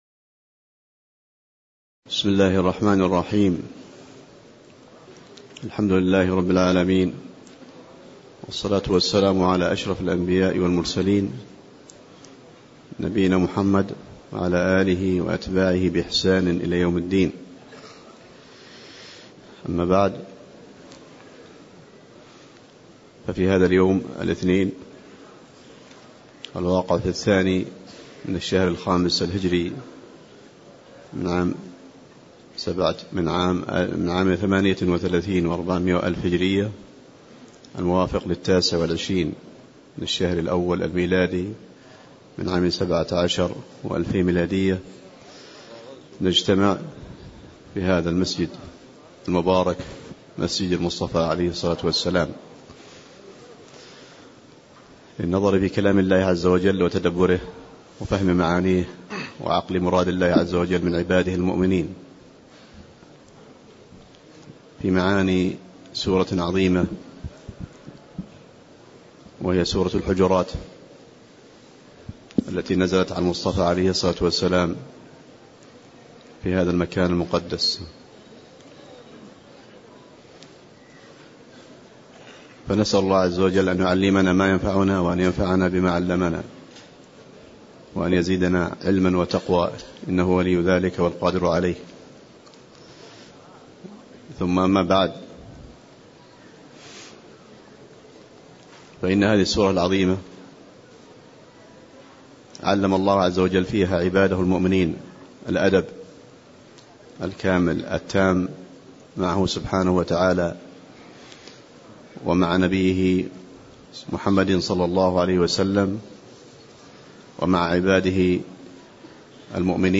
تاريخ النشر ٢ جمادى الأولى ١٤٣٨ هـ المكان: المسجد النبوي الشيخ